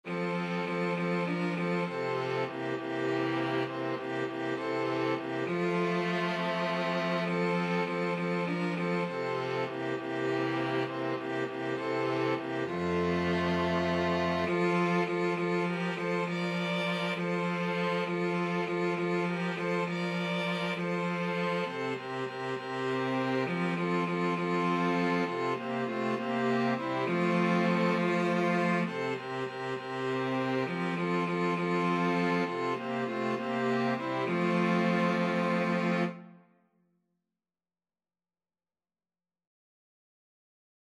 Christian
Violin 1Violin 2ViolaCello
6/8 (View more 6/8 Music)
Classical (View more Classical String Quartet Music)